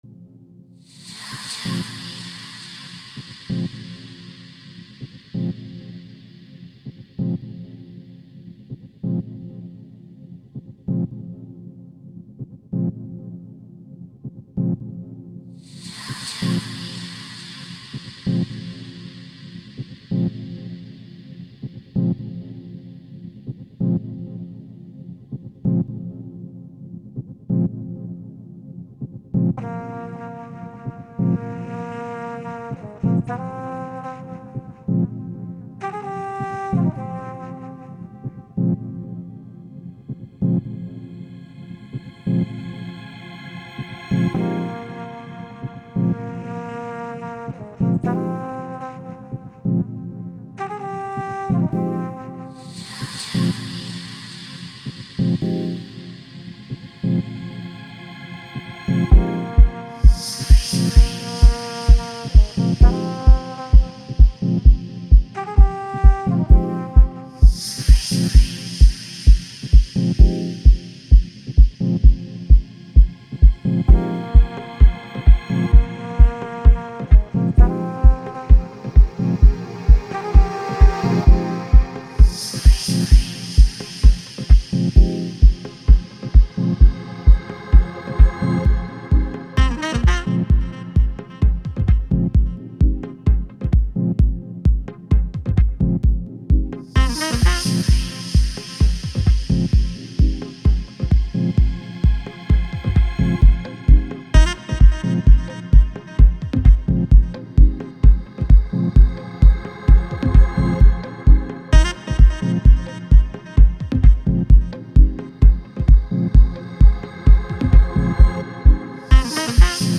Deep